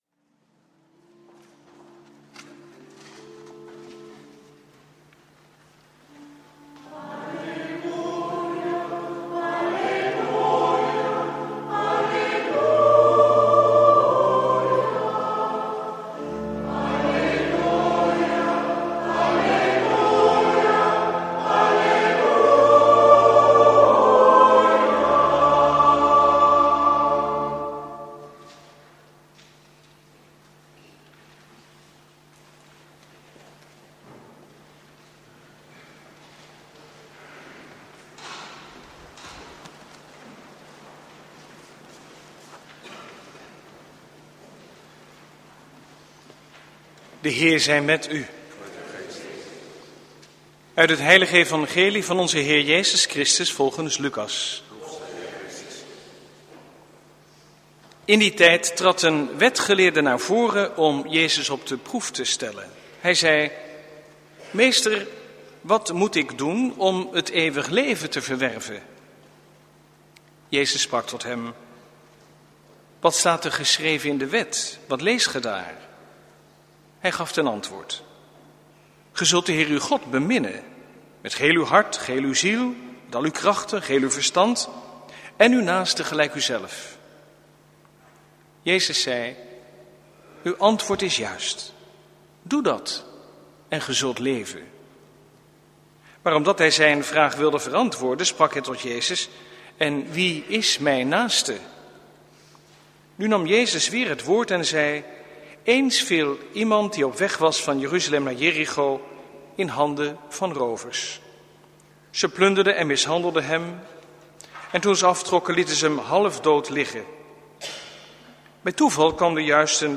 Preek 15e zondag, door het jaar C, 9/10 juli 2016 | Hagenpreken
Lezingen